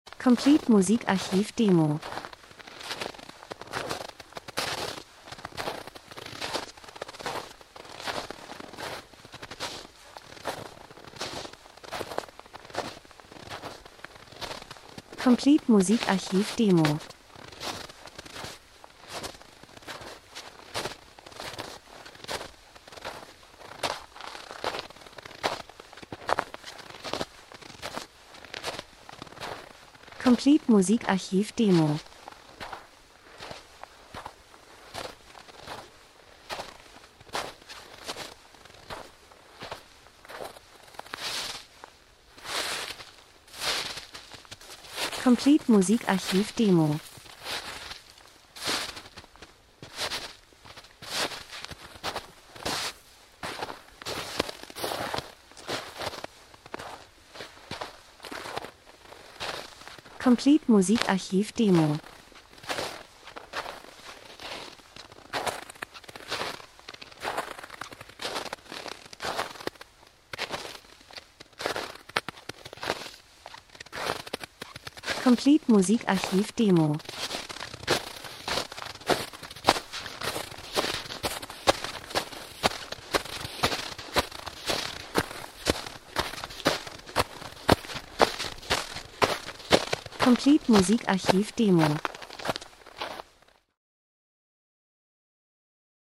Winter - Spaziergang durch den Schnee 01:35